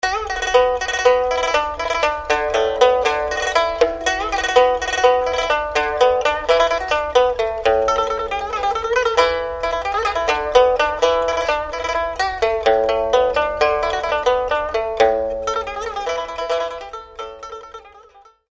pipa.mp3